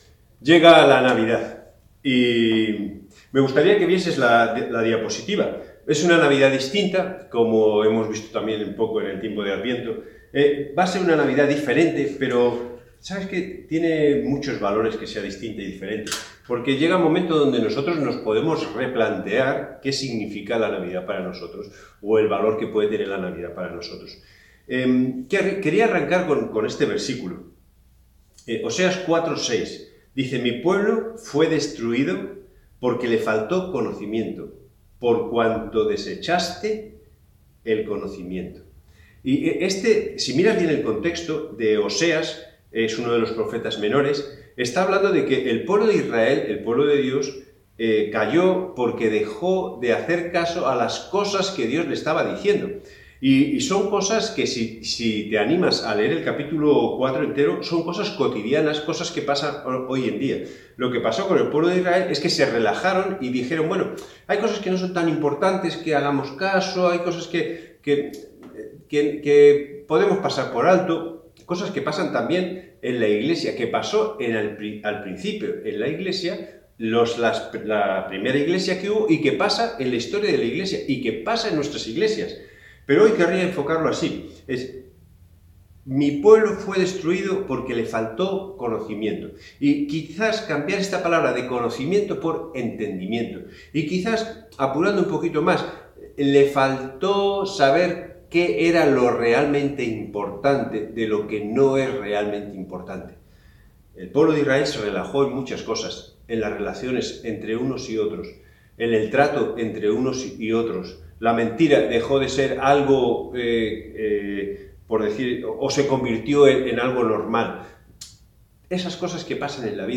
AUDIO_Predicacion.mp3